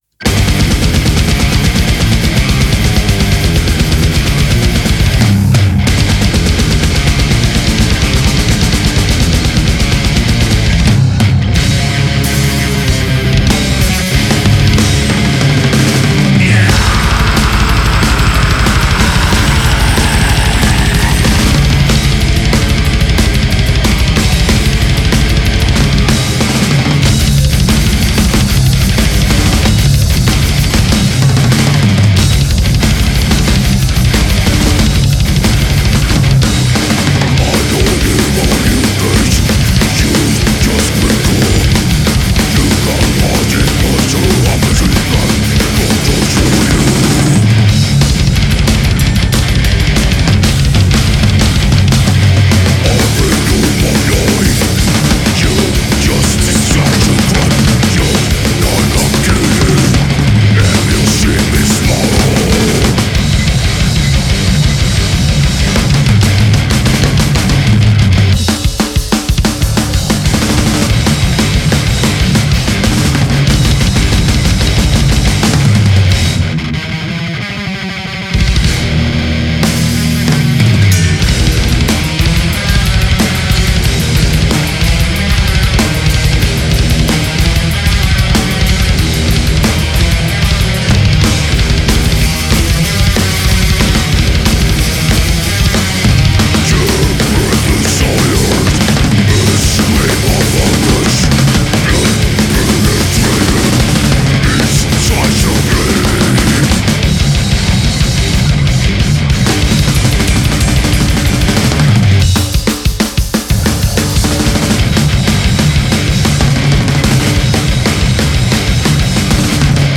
Genre: deathmetal.